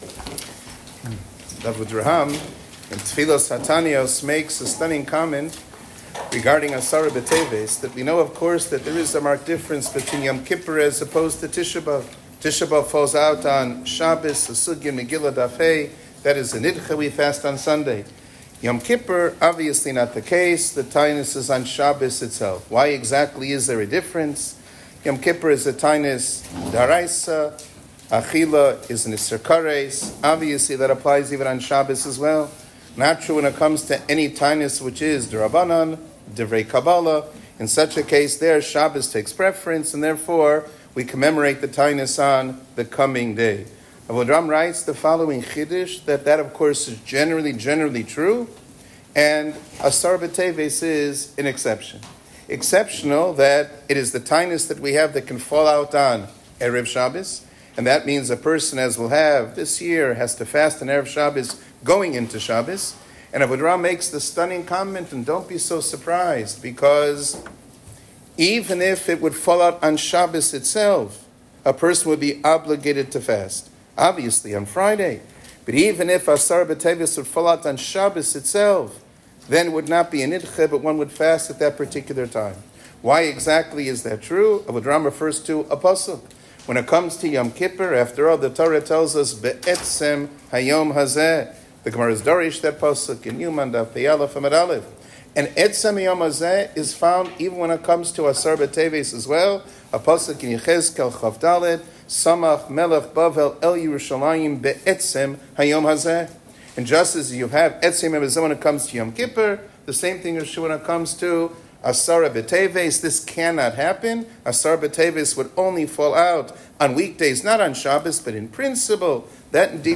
שיעור כללי - עשרה בטבת